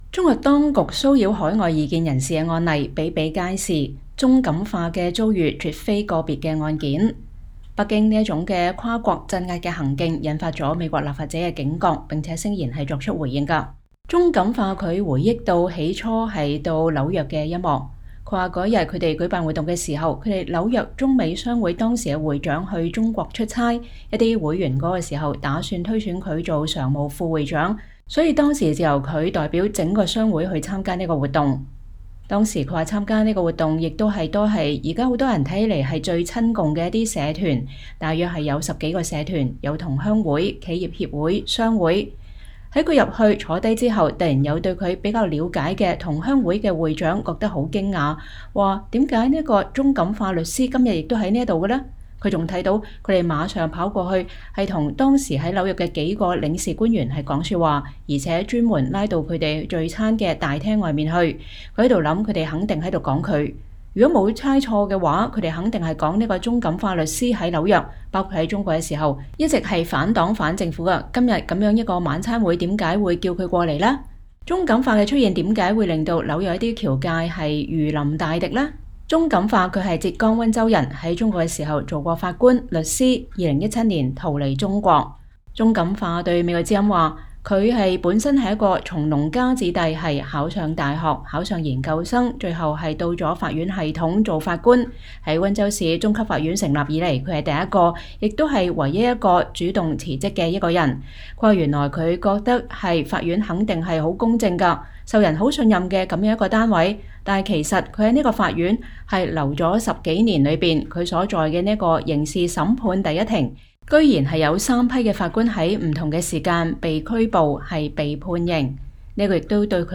原聲帶·鍾錦化：都跑到美國了，怎麼共產黨還跟在屁股後面？